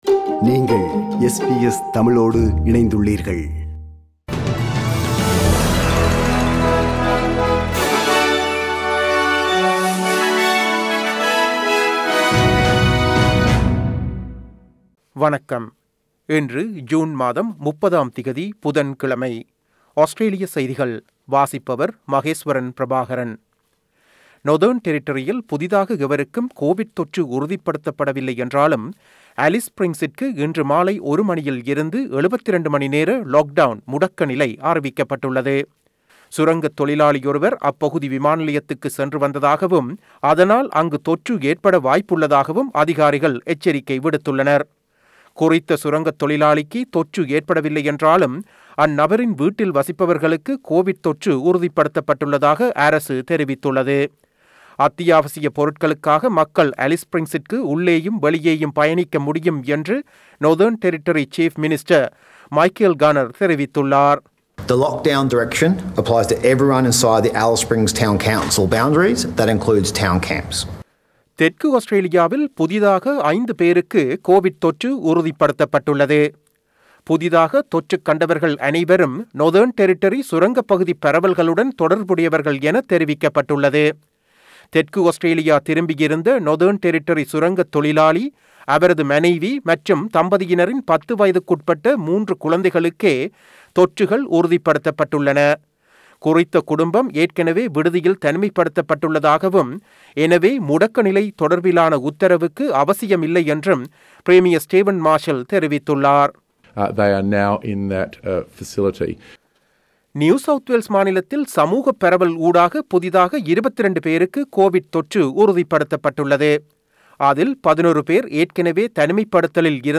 SBS தமிழ் ஒலிபரப்பின் இன்றைய (புதன் கிழமை 30/06/2021) ஆஸ்திரேலியா குறித்த செய்திகள்.